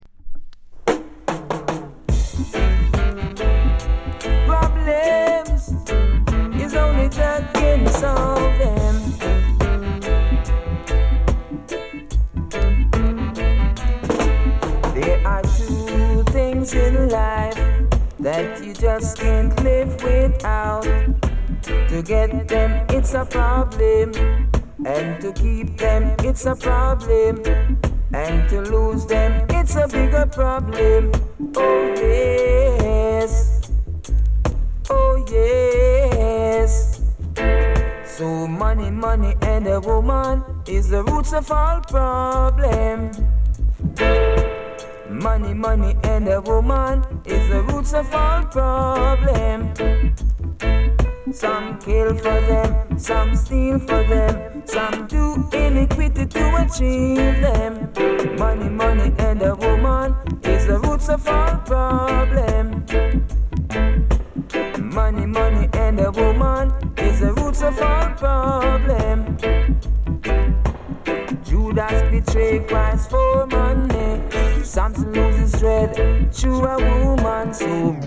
ジャマイカ盤 7inch/45s。